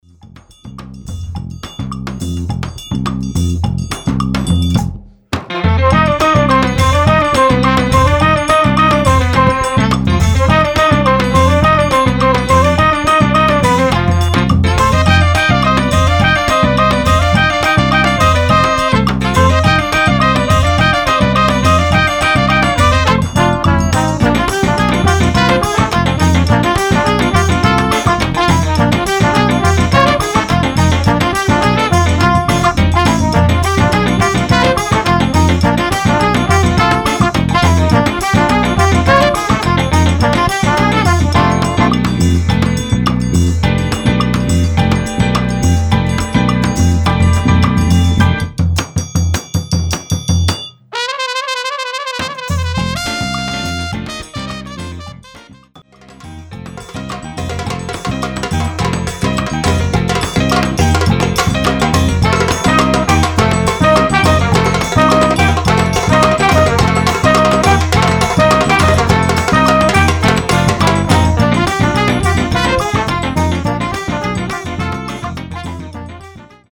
Category: combo (septet)
Style: samba
Solos: open